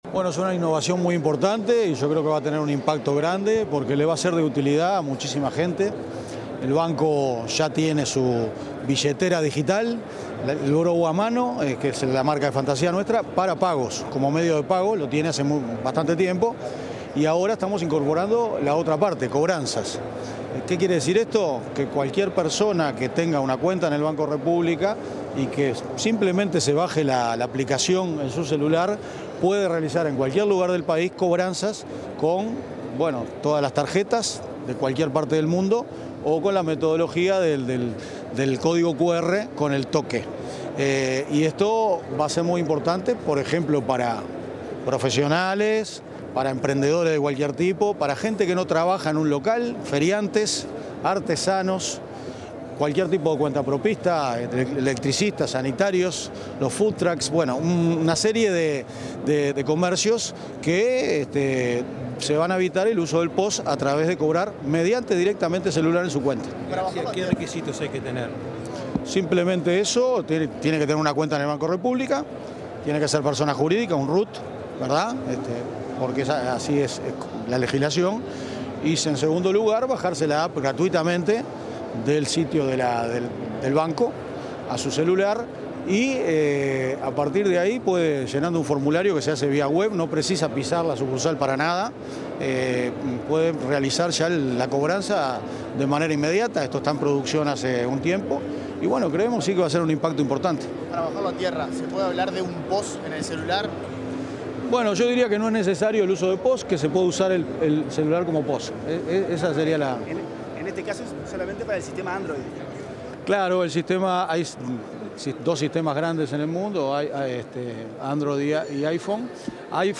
Declaraciones del presidente del BROU, Álvaro García
Tras la presentación de BROU a Mano Comercios, el presidente del Banco de la República (BROU), Álvaro García, efectuó declaraciones a medios